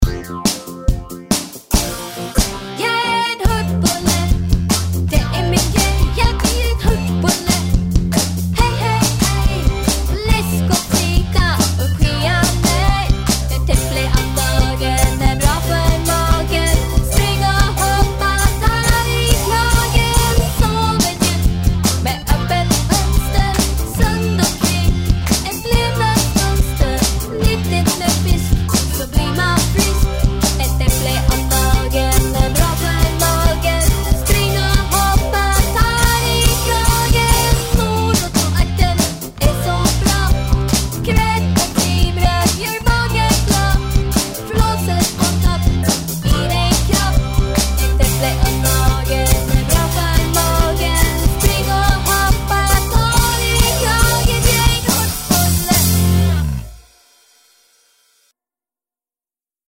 Sångversion